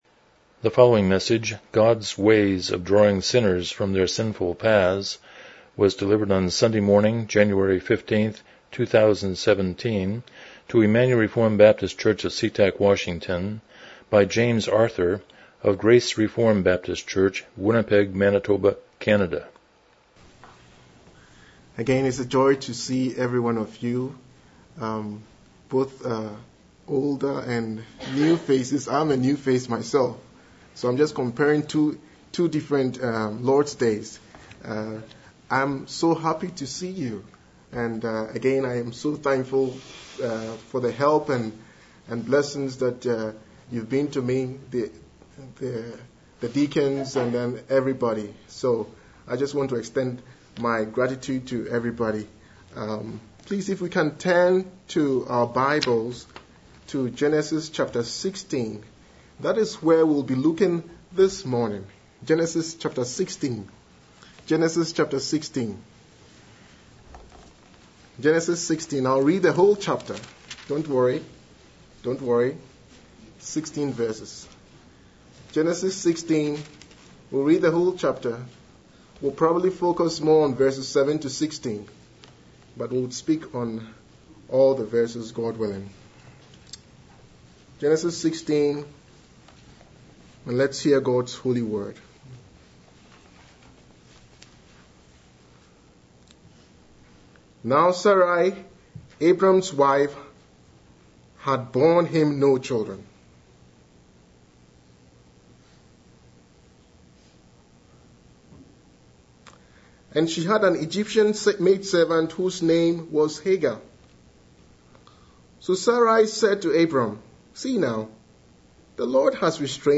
Miscellaneous Service Type: Morning Worship « Christ’s Commission to His People